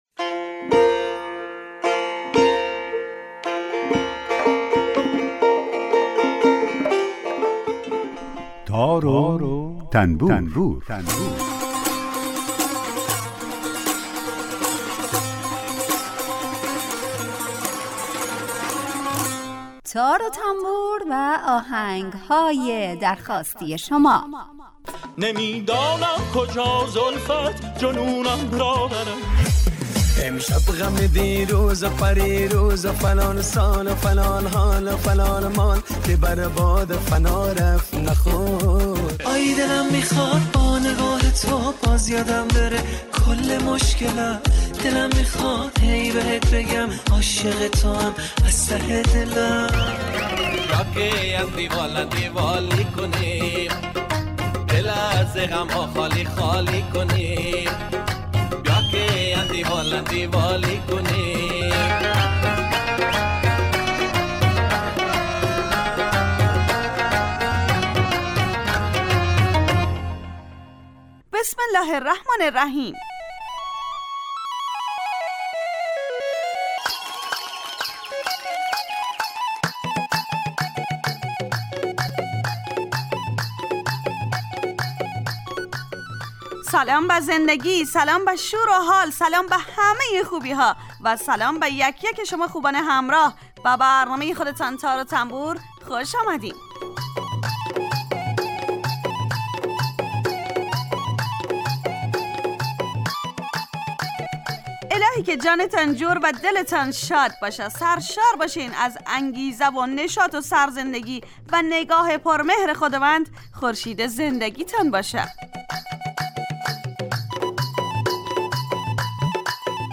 آهنگ های درخواستی